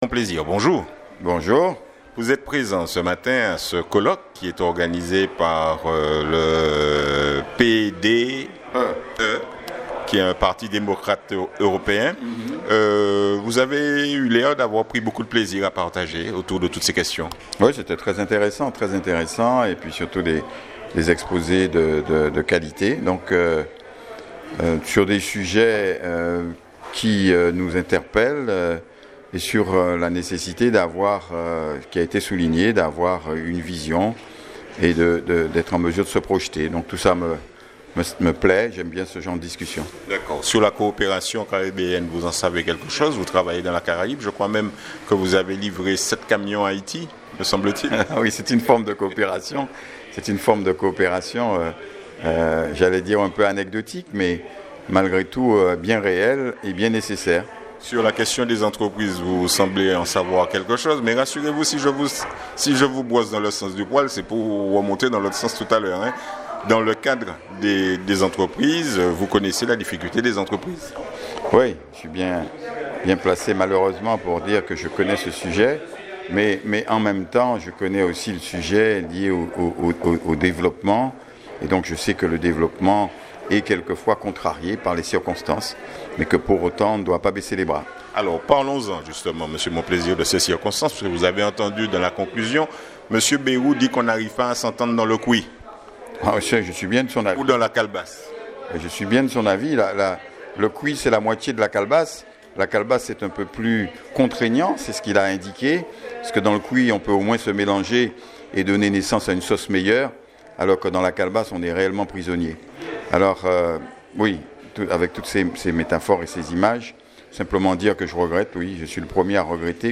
Dans ce reportage audio réalisé durant le colloque du PDE nous avons interrogé Yan Monplaisir sur la question du kankan au CMT .